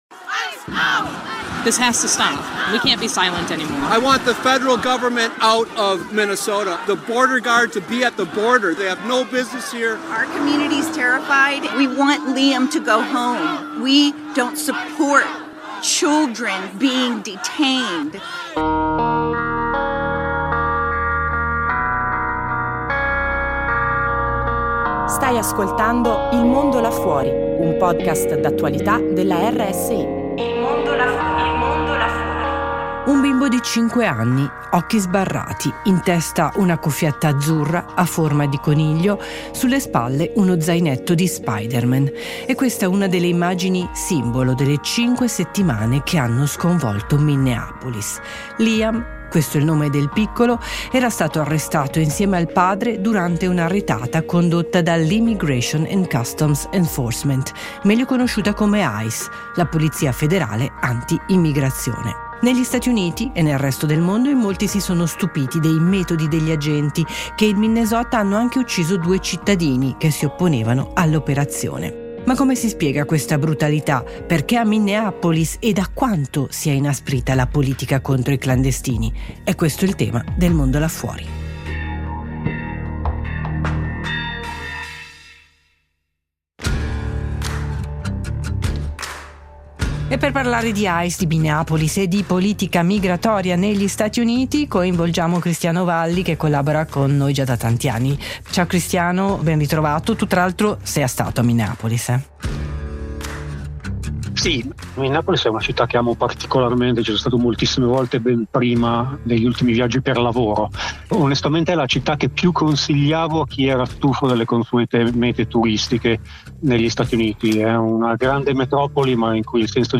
Il racconto del nostro inviato, tra auto fantasma e solidarietà della comunità